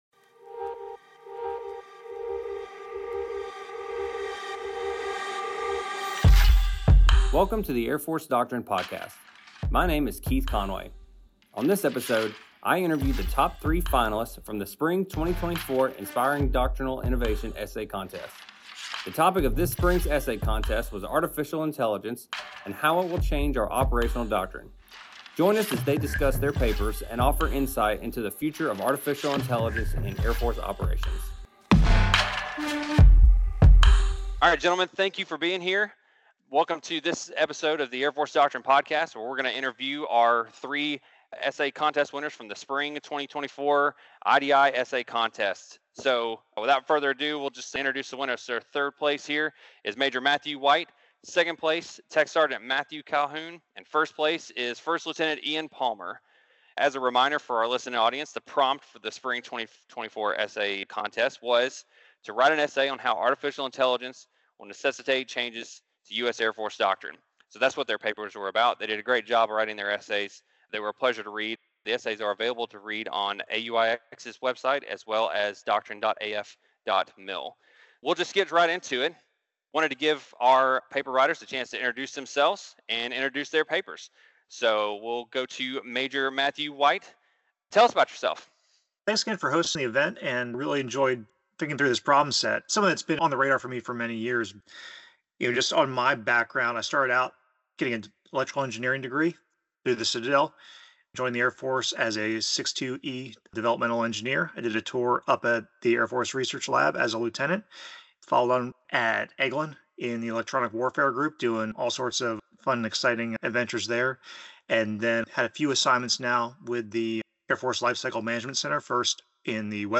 Three talented airmen discuss the possibilities and the challenges of applying artificial intelligence to Air Force operations.